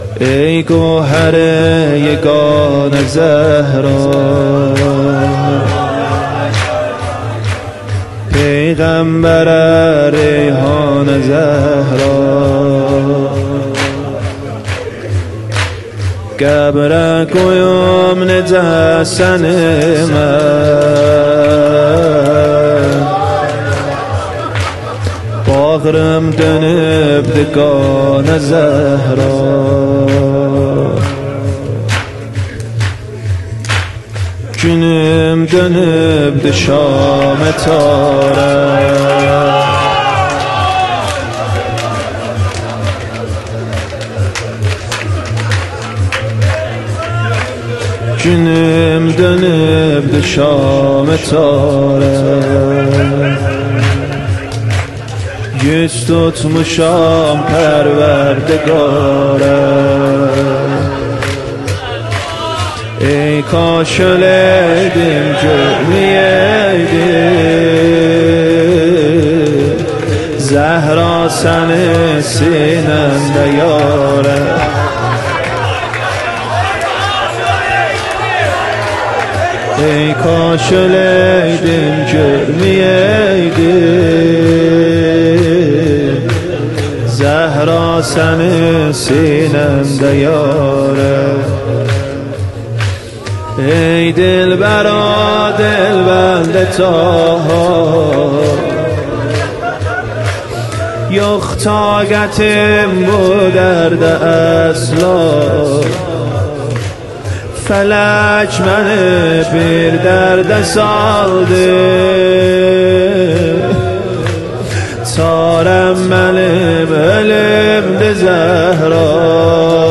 شور پایانی
سینه زنی شور پایانی ای کوهر یگانه زهرا(ترکی
ایام فاطمیه اول - شب سوم